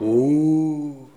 Les sons ont été découpés en morceaux exploitables. 2017-04-10 17:58:57 +02:00 204 KiB Raw Permalink History
oh_03.wav